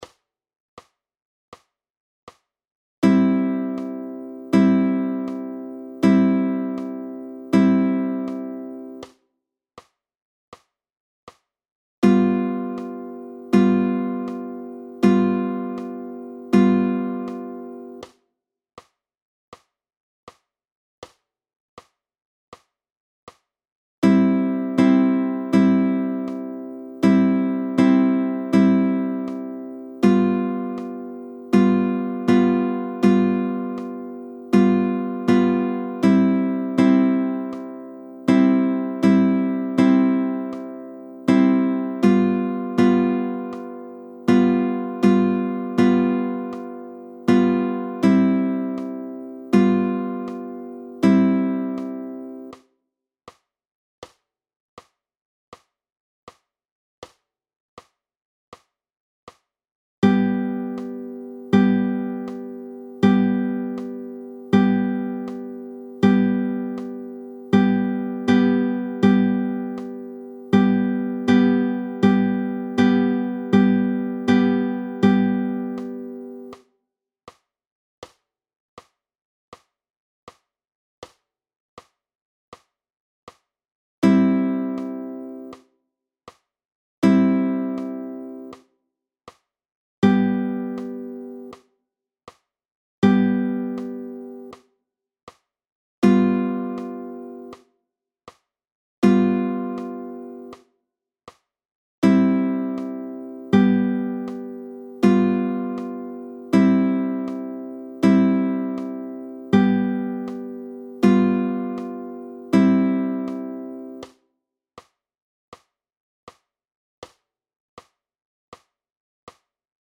– C, G7, G
II.) Ab- und Aufschläge mit Zeigefinger: PDF
Audio, 80 bpm: